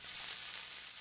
tnt_gunpowder_burning.ogg